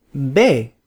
Summary Description Letter b es es.flac English: Pronunciation of letter b from the Spanish alphabet as recorded by a native speaker from Spain. Español: Pronunciación de la letra b ( be ) del alfabeto español grabada por un hablante nativo de España.